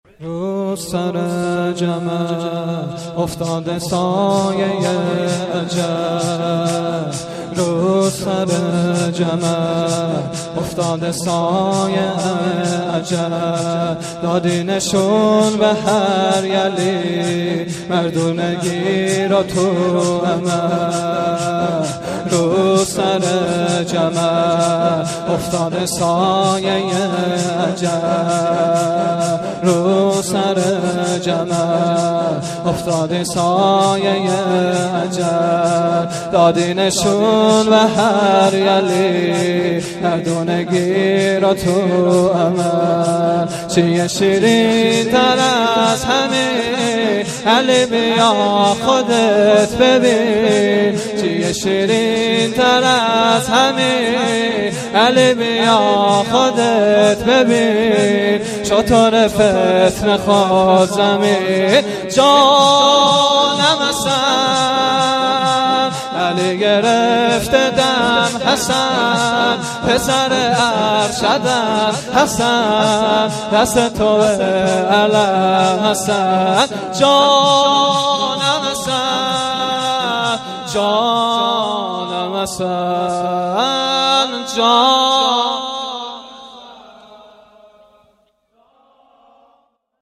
شور| رو سر جمل